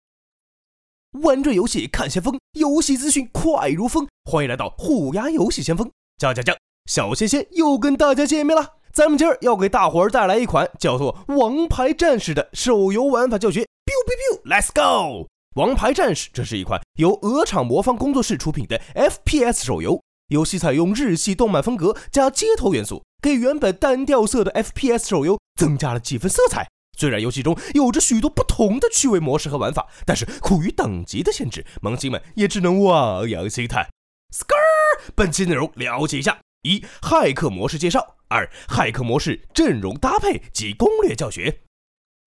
. p* u* D6 K( ~# u7 T6 o5 t解说：